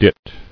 [dit]